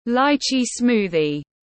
Sinh tố vải tiếng anh gọi là lychee smoothie, phiên âm tiếng anh đọc là /ˈlaɪ.tʃiː ˈsmuː.ði/
Lychee smoothie /ˈlaɪ.tʃiː ˈsmuː.ði/